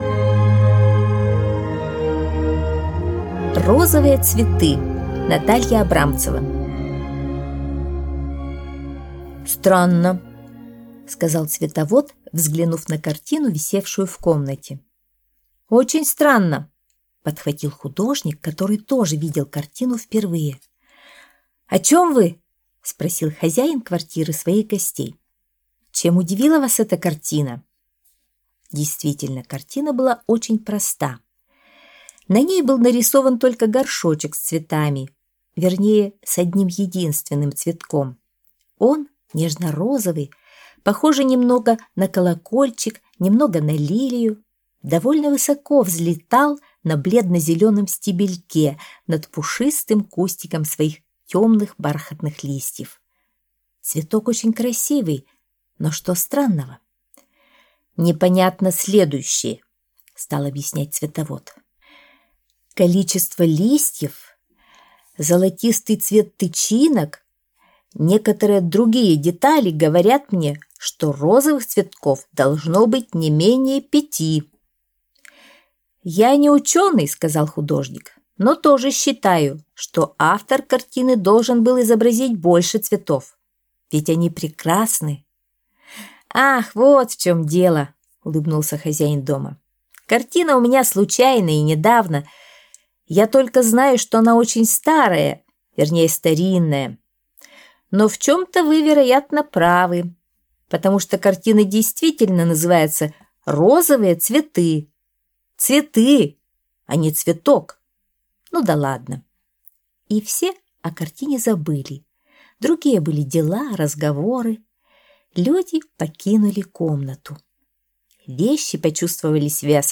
Аудиосказка «Розовые цветы»